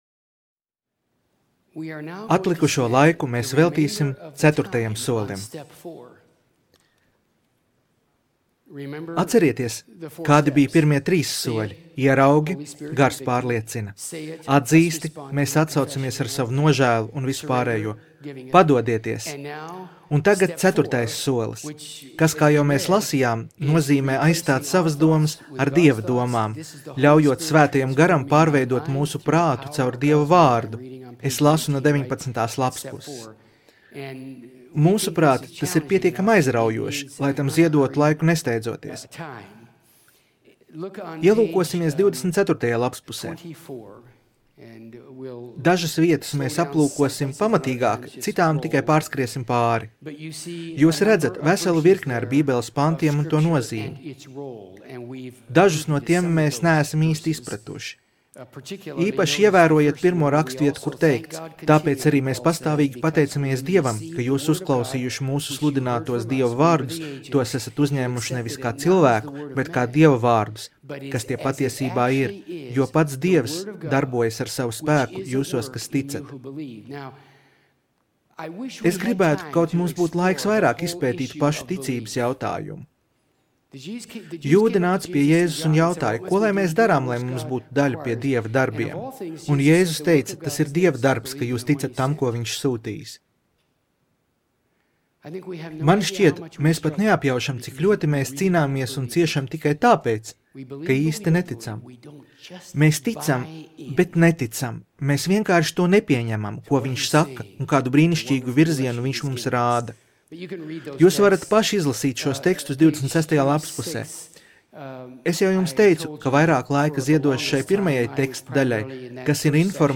Skatīties video Seminārs - Kā pareizi nomirt... un dzīvot, lai par to stāstītu!